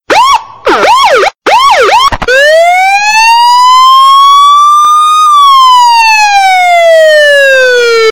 HQ-Police-Siren
HQ-Police-Siren.mp3